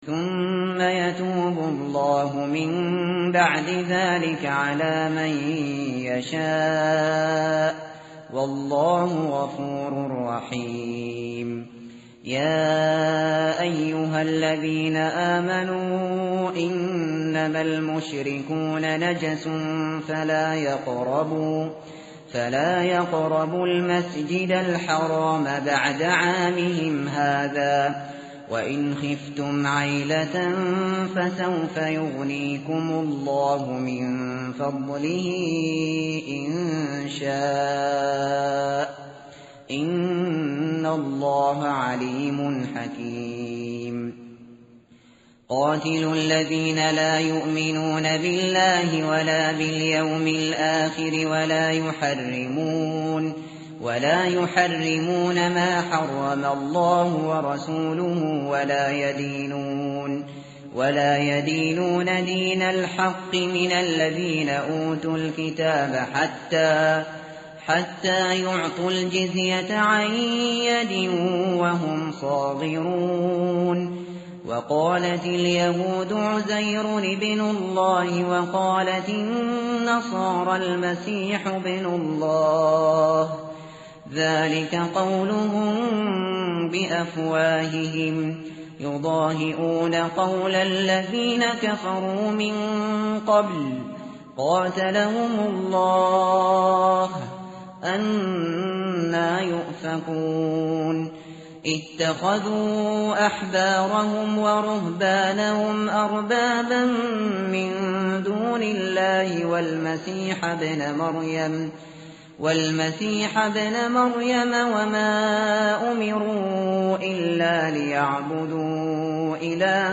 tartil_shateri_page_191.mp3